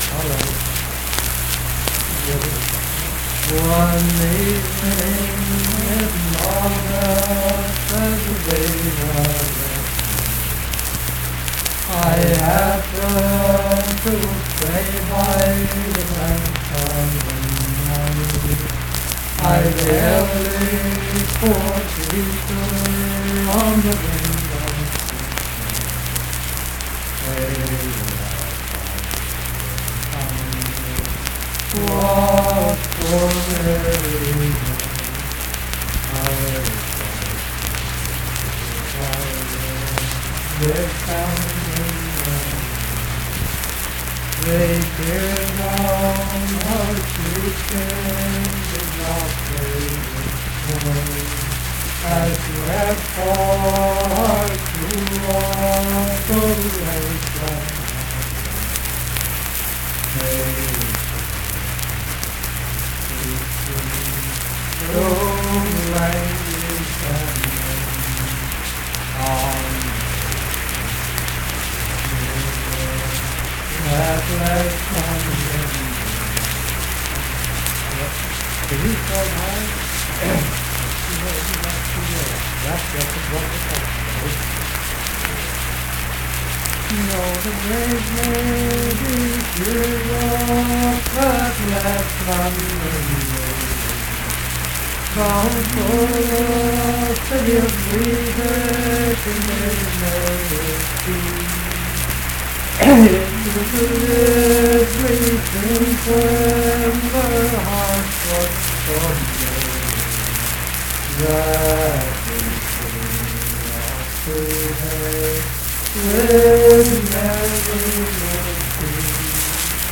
Unaccompanied vocal music
Voice (sung)
Richwood (W. Va.), Nicholas County (W. Va.)